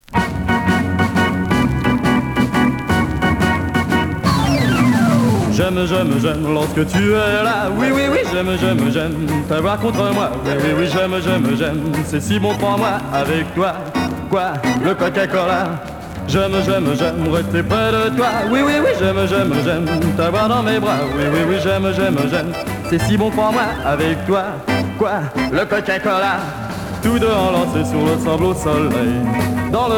Twist et Madison